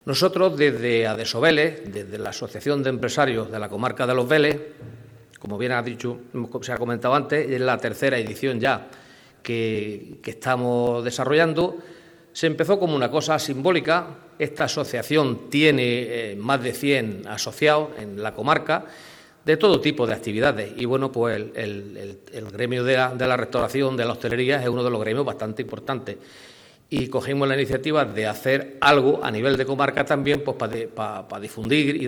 ‘Sabores Almería’ se ha sumado a esta Ruta Gastronómica que organiza la Asociación de Empresarios de la Comarca de Los Vélez en Vélez-Blanco, Vélez-Rubio, María y Chirivel Este evento se ha presentado hoy en la Sala de Prensa del Área de Bienestar Social de la Diputación.